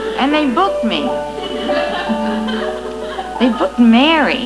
In addition, I am pleased to be able to include several files recorded from the rarely seen episode of "Saturday Night Live" which Louise Lasser hosted on July 24, 1976.
The following are from her second monologue.